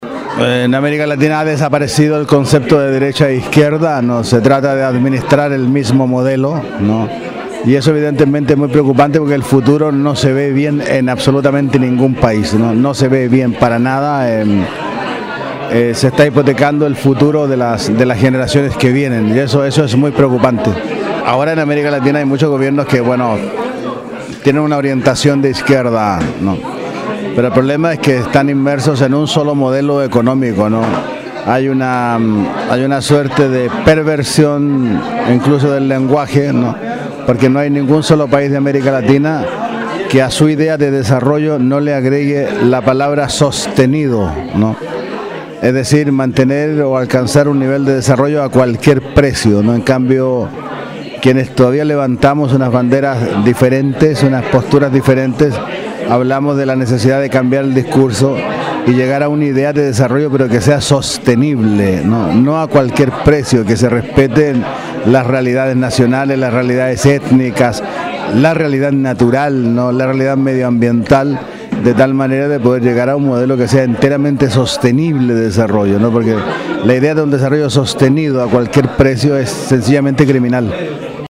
Luis Sepúlveda, escritor chileno, en entrevista con swissinfo.